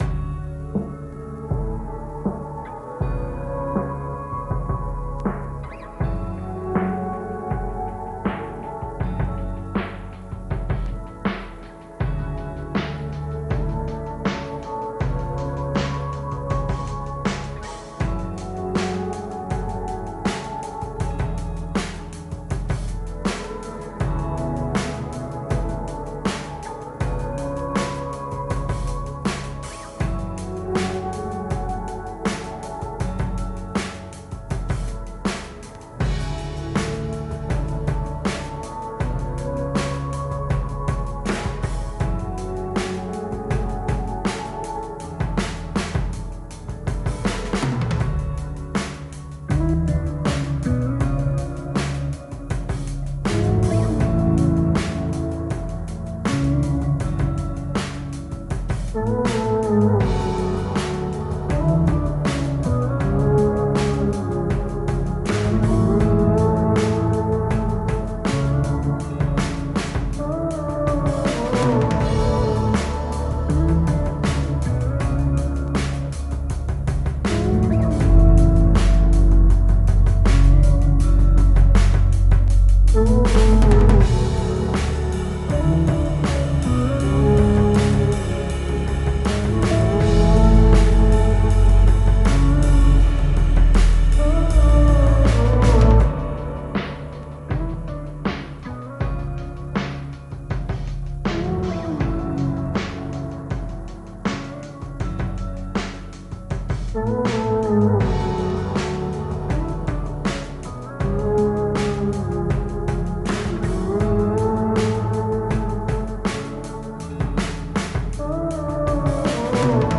Musique chill libre de droit pour vos projets.